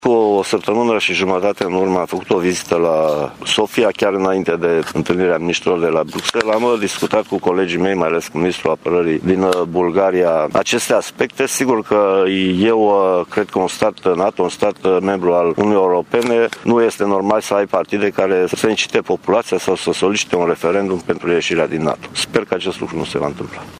Prezent azi, la Tîrgu-Mureş, ministrul s-a declarat încrezător că nu va avea loc un referendum în Bulgaria pentru ieșirea țării din NATO.